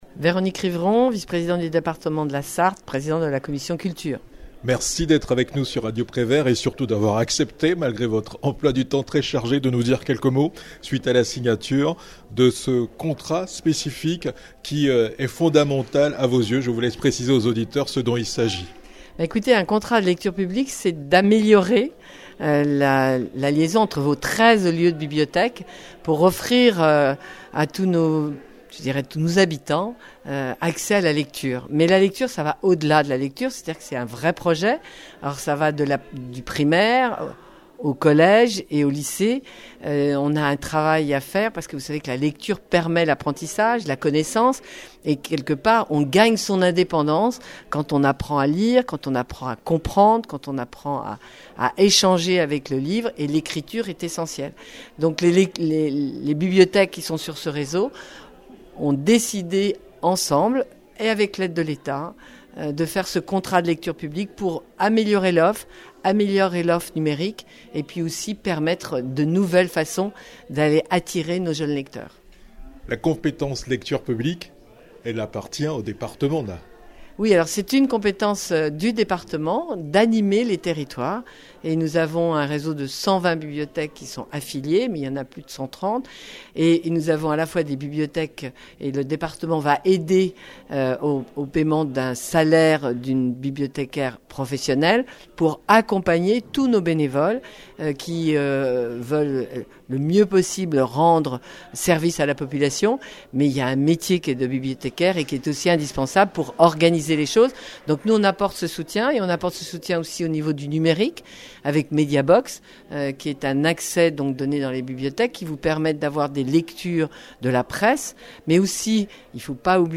A l'issue de la cérémonie, François Boussard, Président de l'intercommunalité, Véronique Ortet, sous-préfète de l'arrondissement de La Flèche, Véronique Rivron, 1ère vice-présidente du Conseil départemental de la Sarthe, présidente de la Commission vie associative, culture, sport, tourisme et patrimoine, et Eric Martineau, député de la 3e circonscription de la Sarthe ont mis en exergue le rôle fondamental de la lecture dans l'émancipation du citoyen.